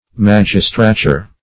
Magistrature \Mag"is*tra`ture\, n.